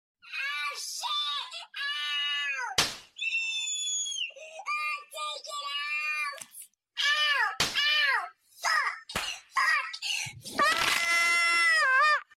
I wanted to hear noob crying sound and I saw this....